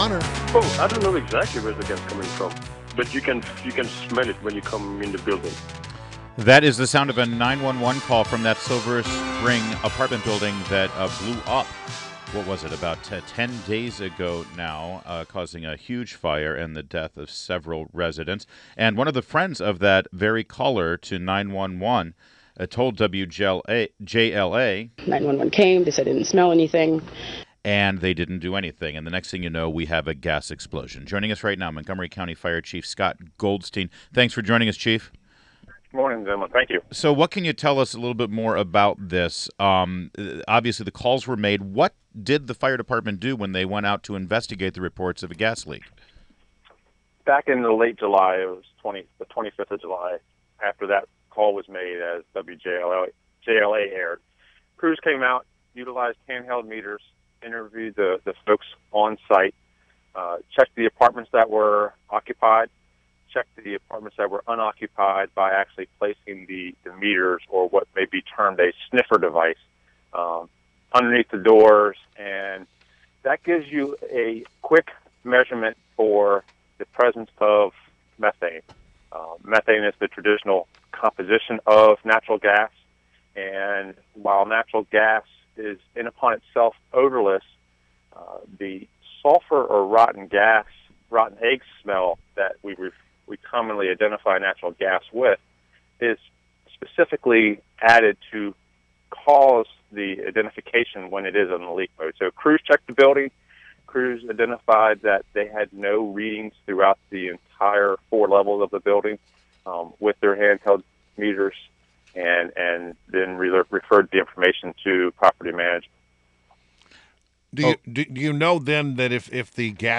WMAL Interview - MoCo Fire Chief SCOTT GOLDSTEIN - 08.24.16